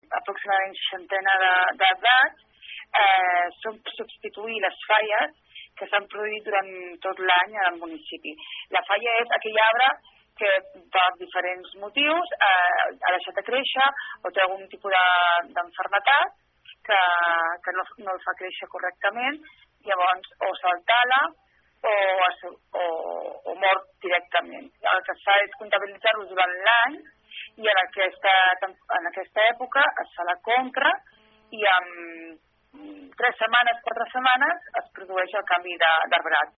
En parla Mireia Castellà, regidora de Medi Ambient de l’Ajuntament de Malgrat de Mar.